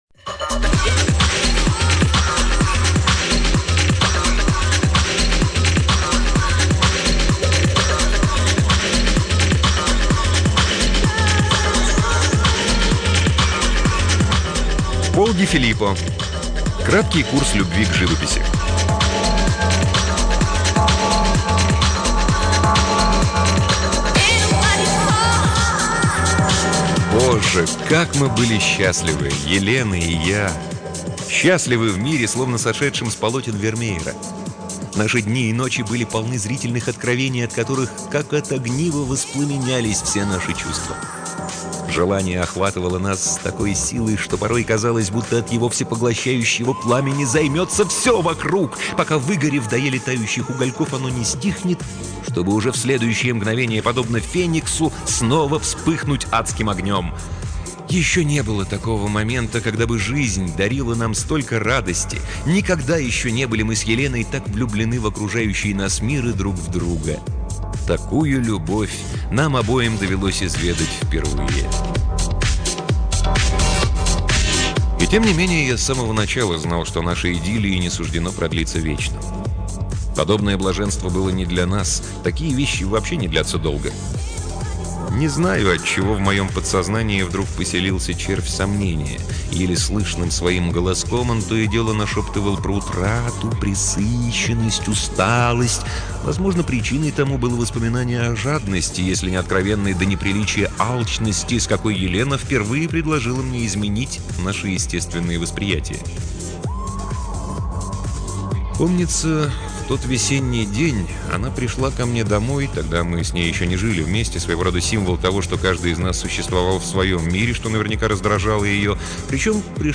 Аудиокнига Пол Ди Филиппо — Краткий курс любви к живописи